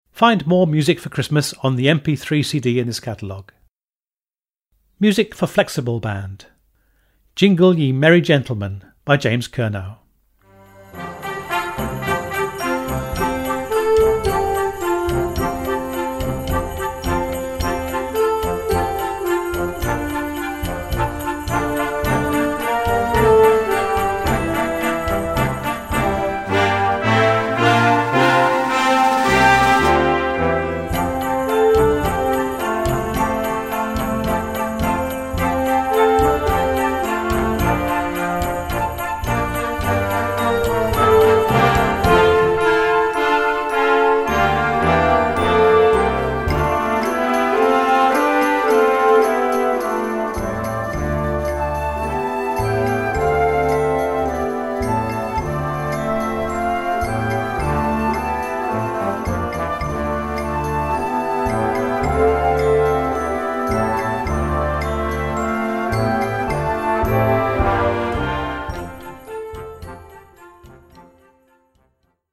Category: CHRISTMAS MUSIC - Grade 1.0